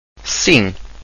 Sim   Sim[ng]